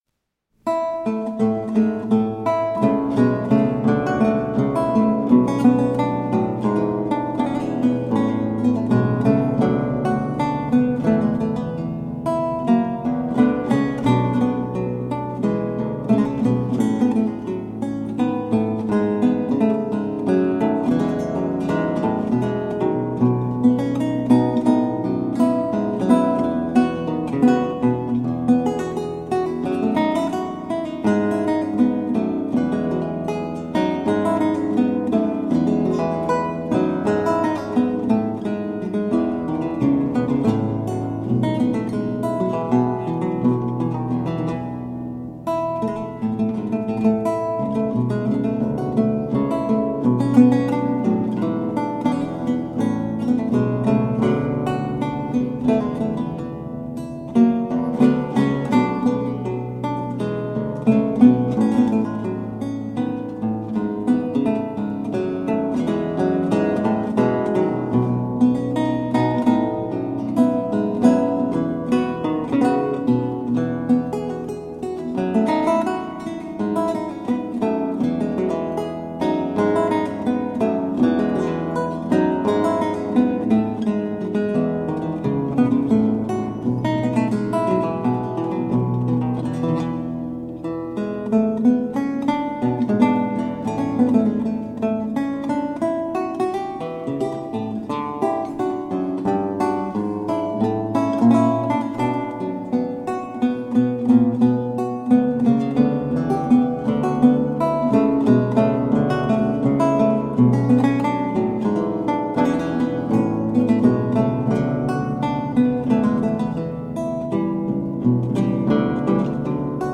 A marvelous classical spiral of lute sounds.